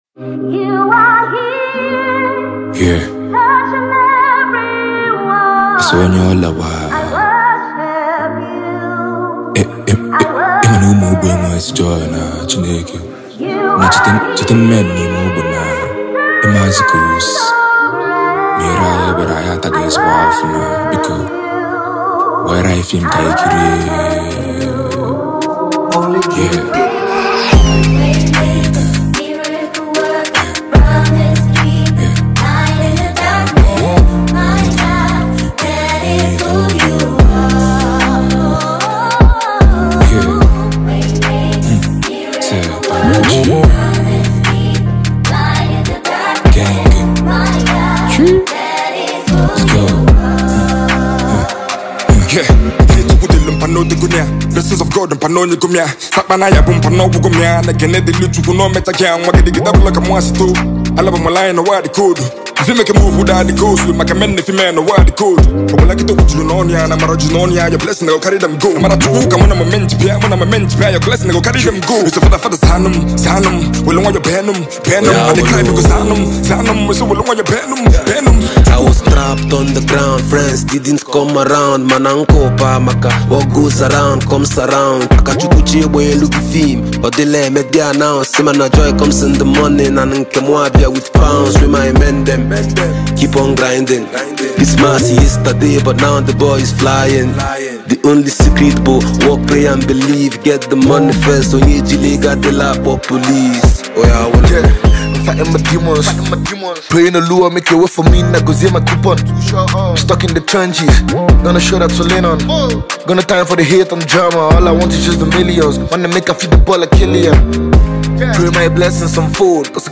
Indegenous Igbo Rapper
Hardcore Igbo Rap
Refix